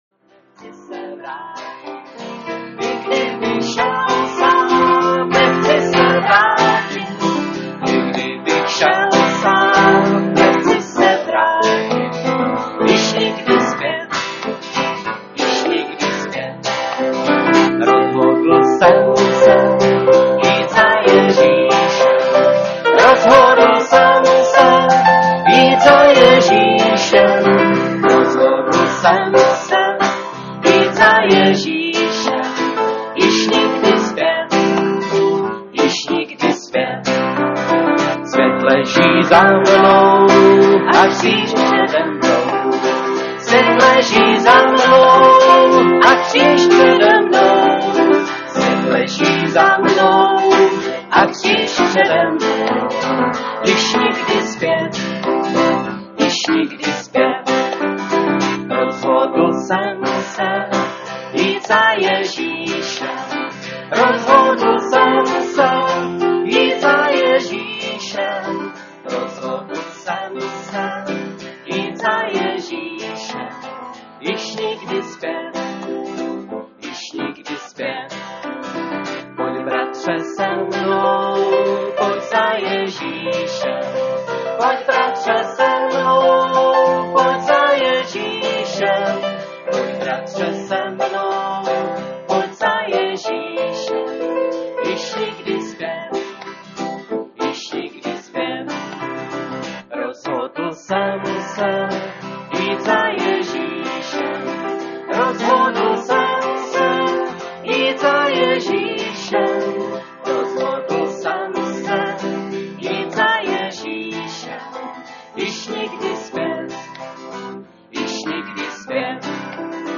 Konference sester Nevzpomínejte na věci dřívější 2007